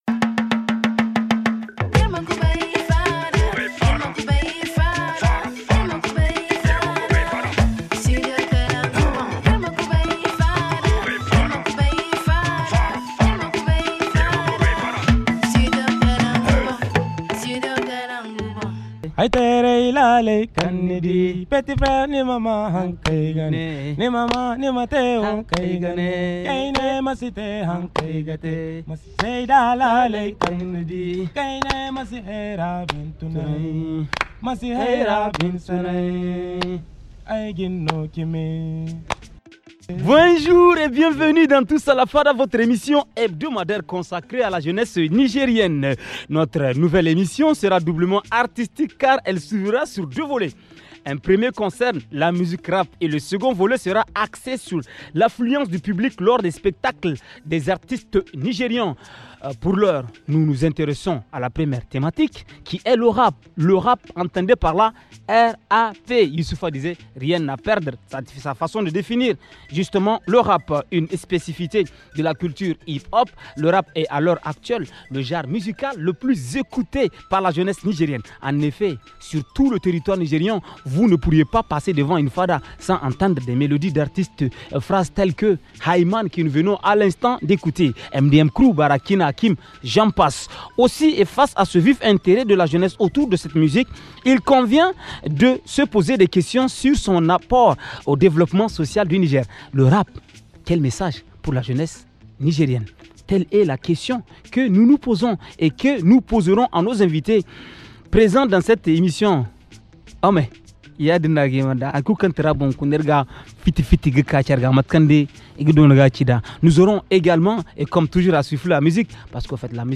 Réponses avec les invités